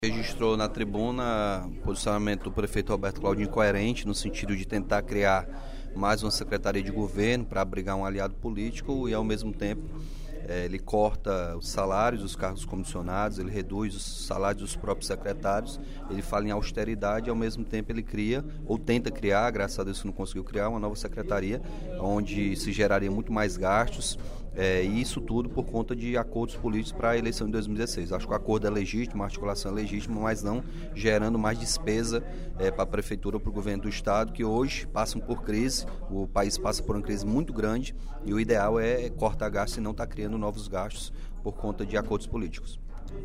O deputado Capitão Wagner (PR) criticou, durante o primeiro expediente da sessão plenária desta quinta-feira (05/11), a ligação entre o deputado federal Adail Carneiro (PHS-CE) e o Governo do Estado.